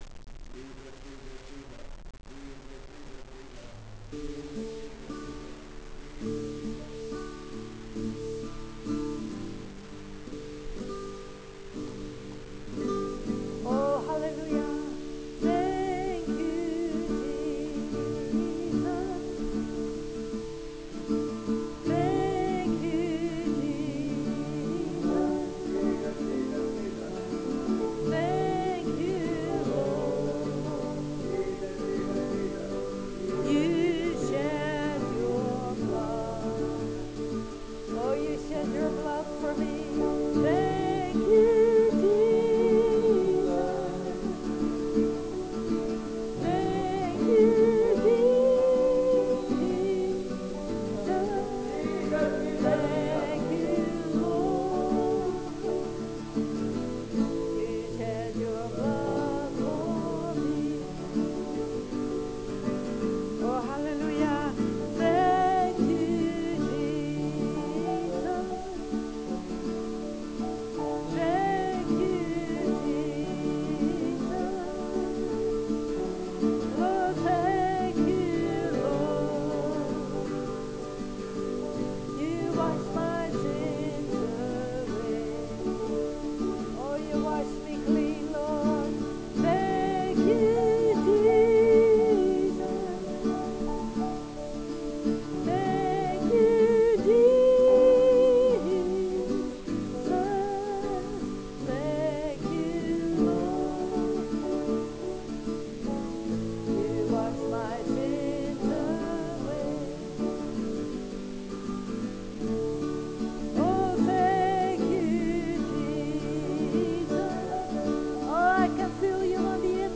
Worship - 6 - Dwelling Place Open Heaven Worship Center & Open Heaven RHEMA Application School